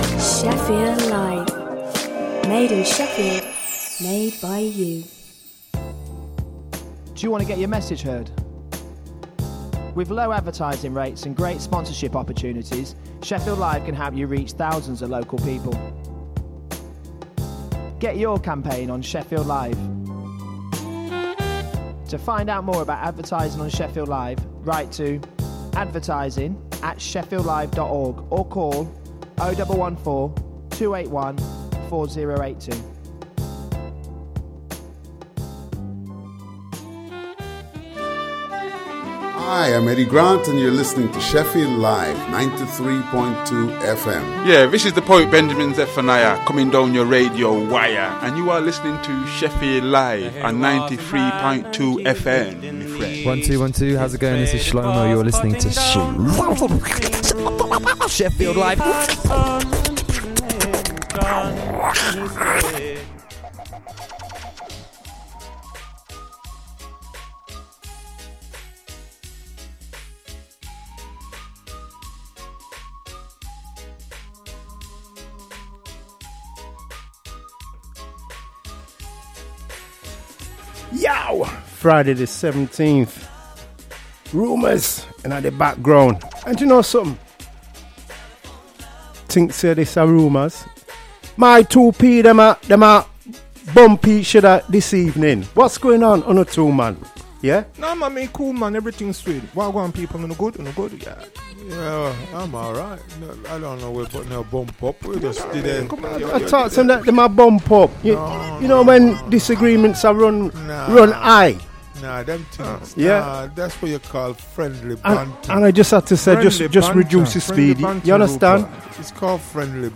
Talking Balls is a tongue in cheek sports chat show looking at the latest sports news and stories, with both interviews, previews and competitions